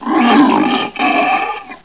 00127_Sound_lionceau.mp3